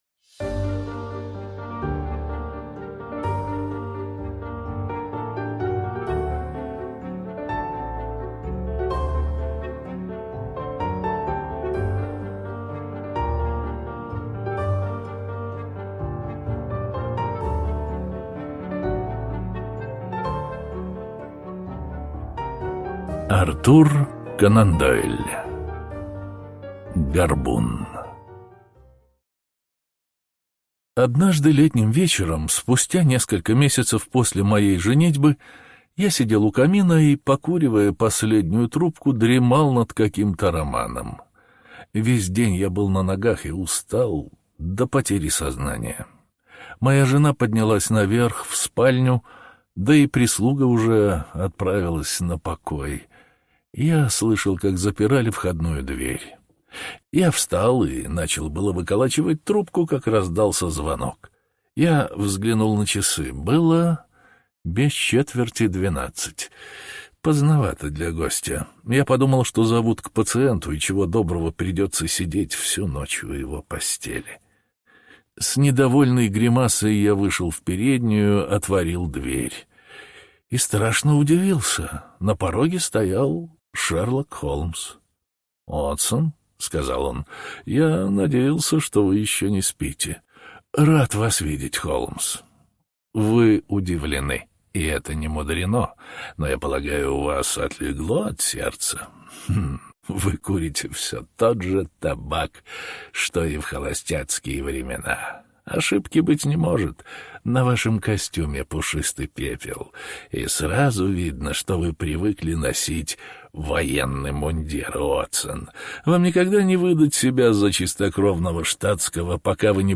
Горбун — слушать аудиосказку Артур Конан Дойл бесплатно онлайн